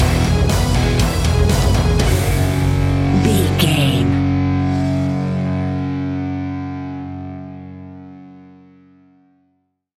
Epic / Action
Fast paced
Aeolian/Minor
F#
hard rock
heavy metal
scary rock
Heavy Metal Guitars
Metal Drums
Heavy Bass Guitars